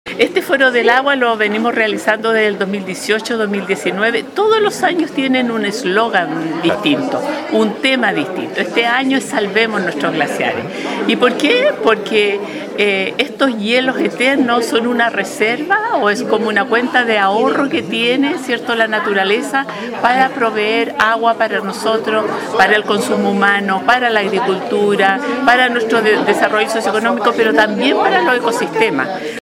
En el marco del Día Mundial del Agua, cada 22 de marzo, el Centro de Recursos Hídricos para la Agricultura y la Minería, Crhiam, organizó una nueva edición del Foro del Agua, con el título “Salvemos nuestros glaciares”.